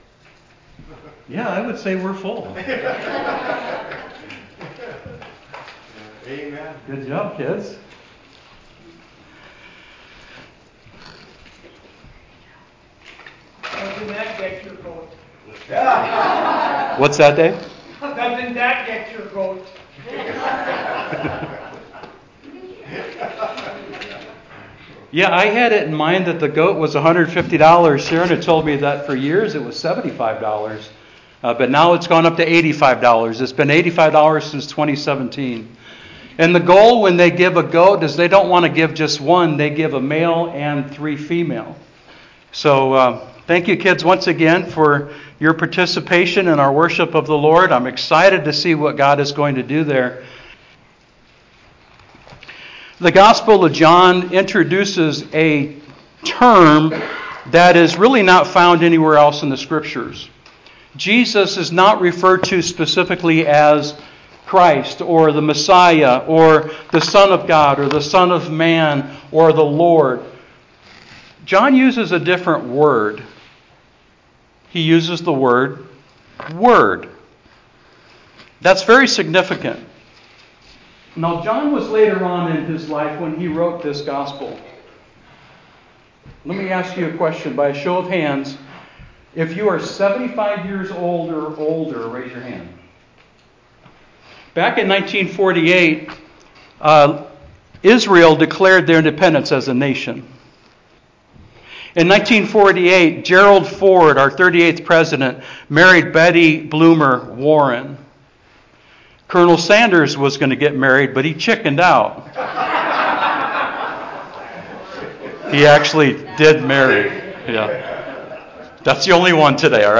A study of John